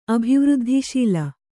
♪ abhivřddhiśila